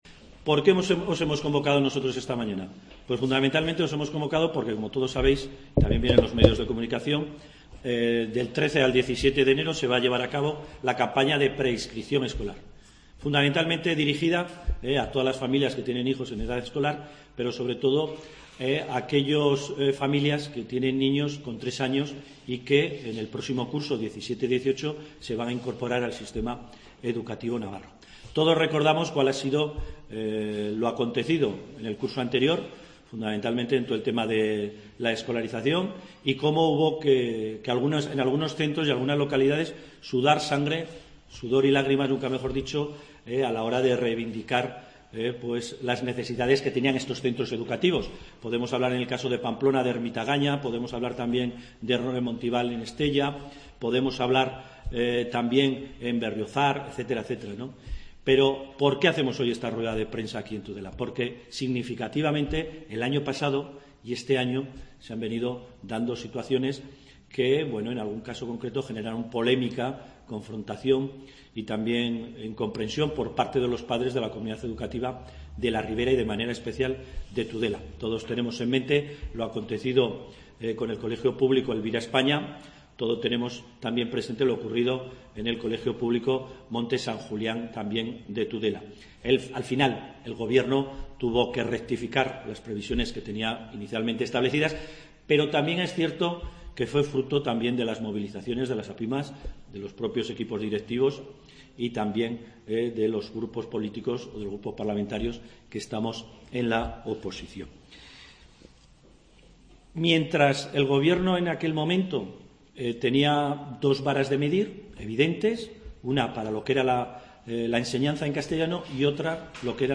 AUDIO: Rueda de prensa del Parlamentario Alberto catalán, hoy en Tudela, sobre politica de Educación en la Ribera.